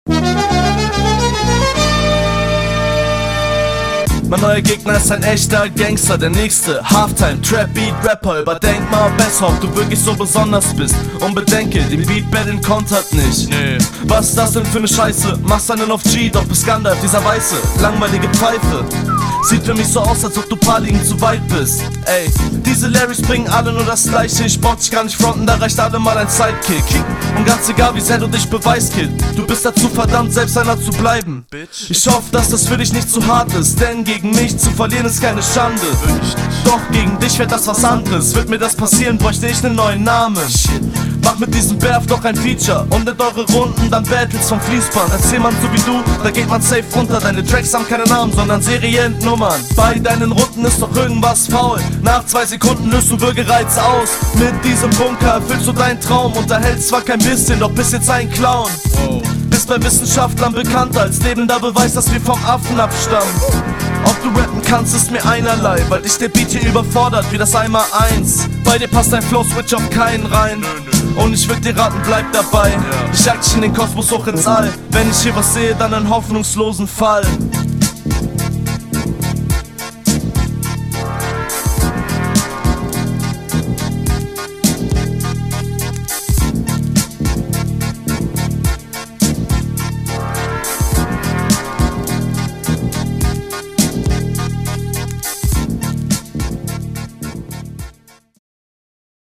Der Stimmeinsatz ist okay, aber nicht so meins.